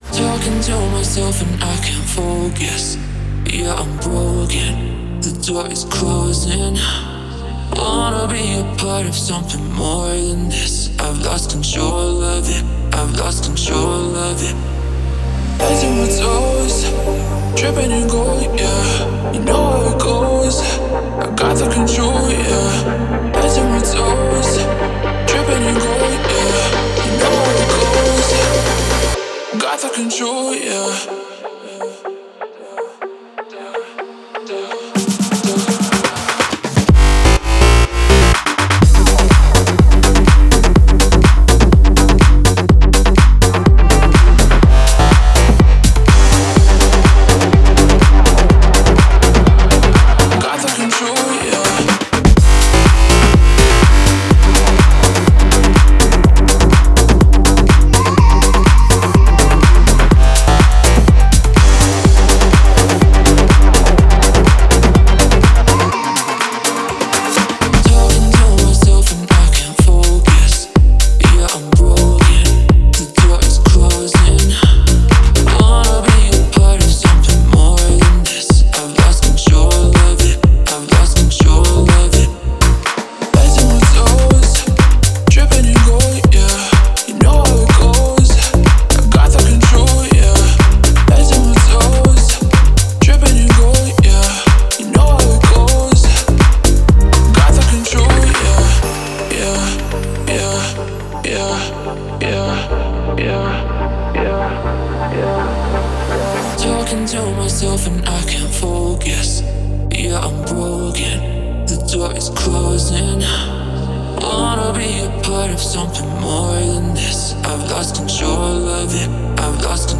это динамичная трек в жанре электро-поп